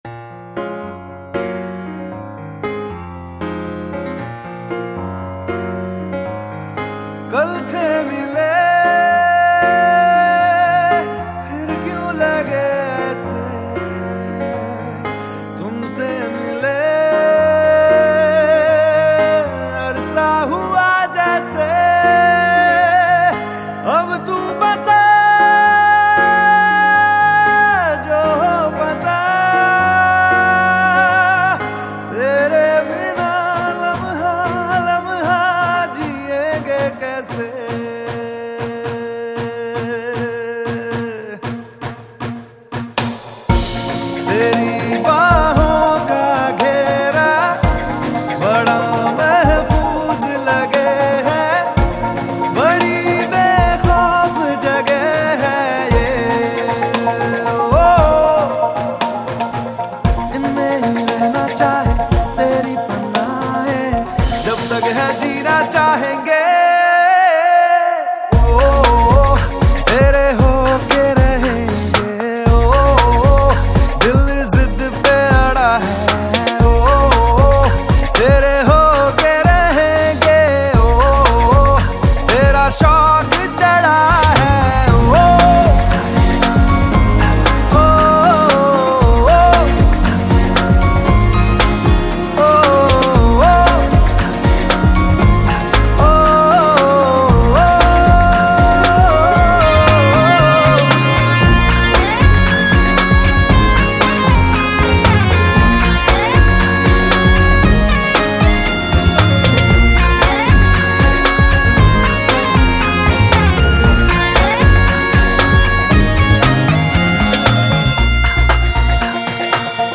Bollywood Mp3 Music 2014